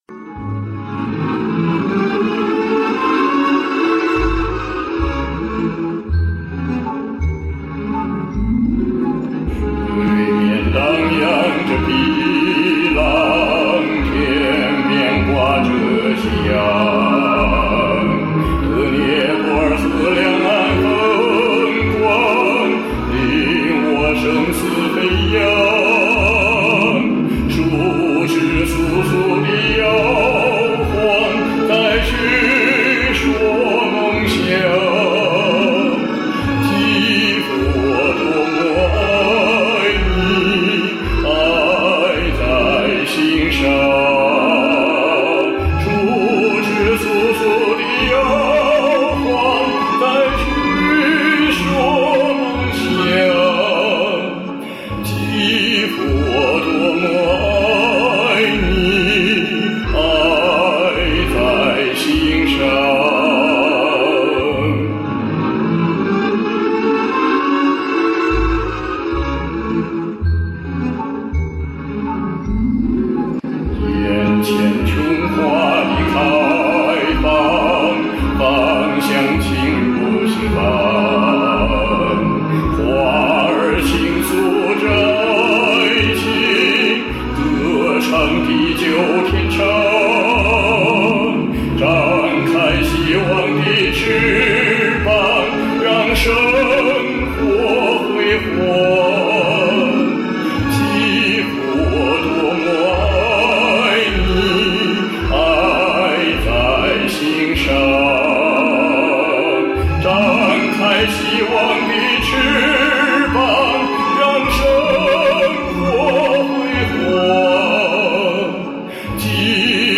咬字和发声都不是很到位